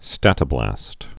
(stătə-blăst)